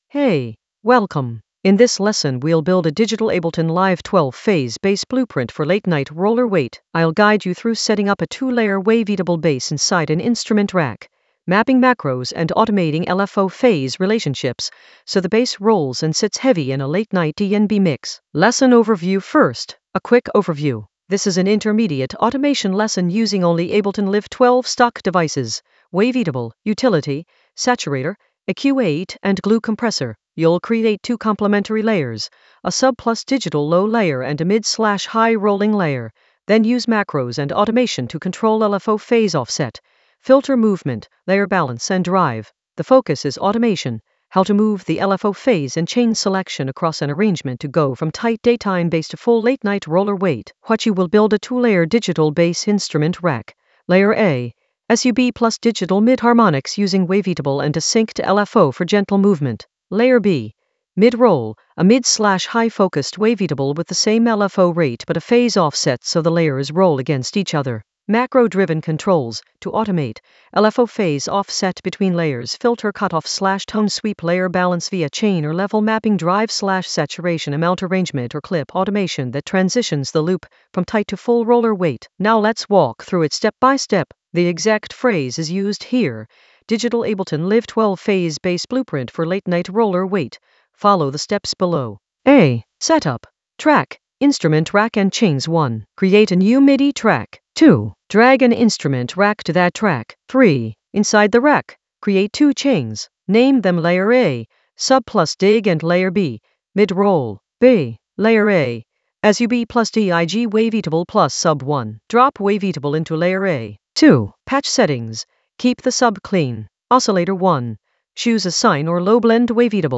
An AI-generated intermediate Ableton lesson focused on Digital Ableton Live 12 phase bass blueprint for late-night roller weight in the Automation area of drum and bass production.
Narrated lesson audio
The voice track includes the tutorial plus extra teacher commentary.